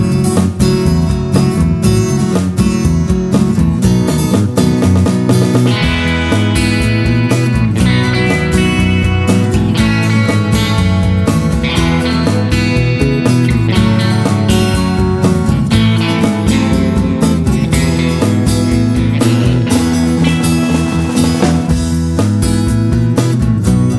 With Harmony Pop (1960s) 4:04 Buy £1.50